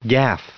added pronounciation and merriam webster audio
967_gaffe.ogg